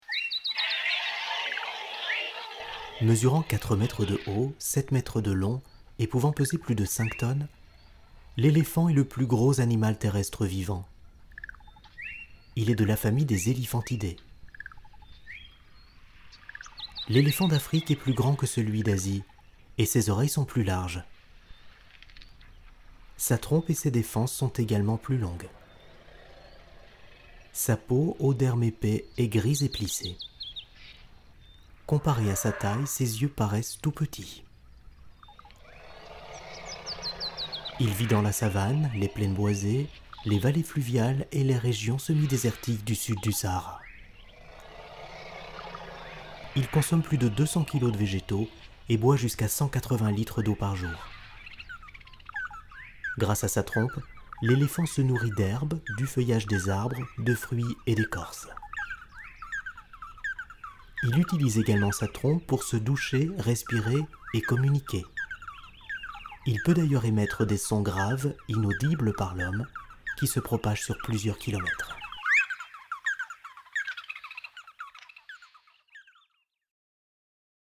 Kein Dialekt
Sprechprobe: Sonstiges (Muttersprache):